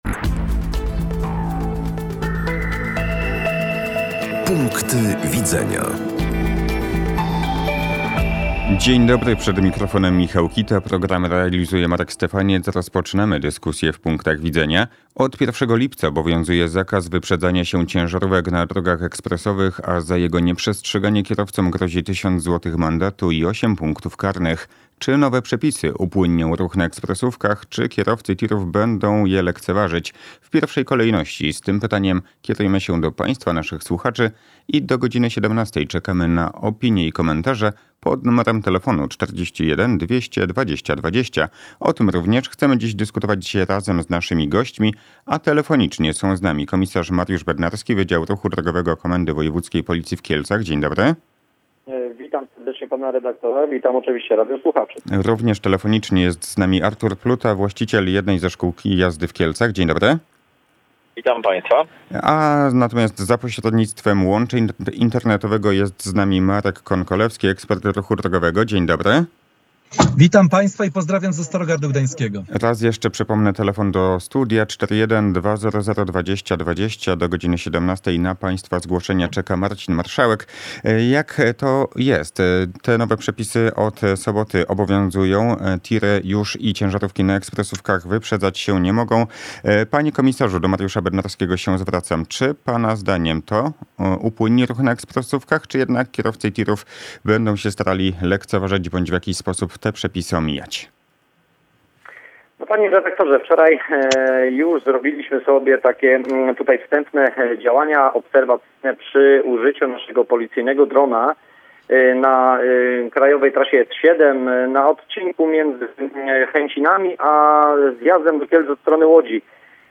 – Wypowiedź Donalda Tuska w kwestii uchodźców miała obnażyć obłudę rządu Prawa i Sprawiedliwości, może była nieelegancka, ale nie mogę nazwać jej ksenofobiczną – stwierdził w Raporcie Parlamentarnym na antenie Radia Kielce poseł Andrzej Szejna, świętokrzyski parlamentarzysta Nowej Lewicy.